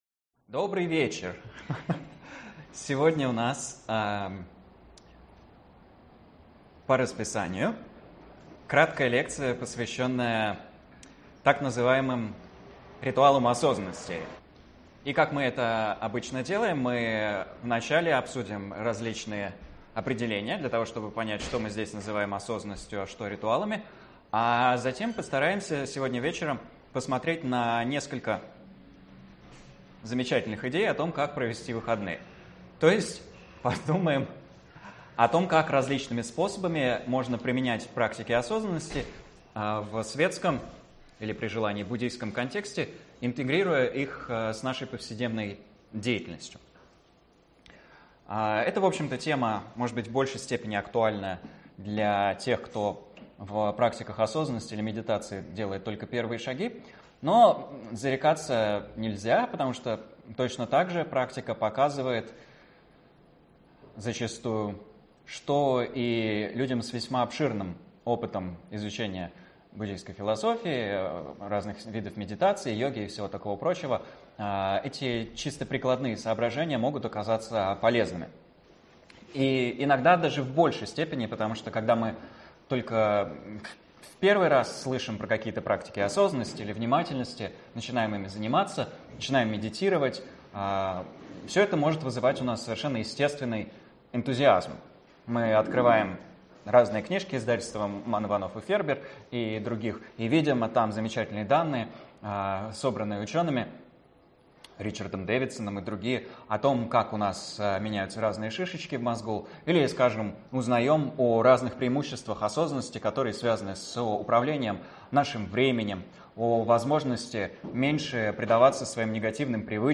Аудиокнига Ритуалы осознанности | Библиотека аудиокниг